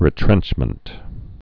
(rĭ-trĕnchmənt)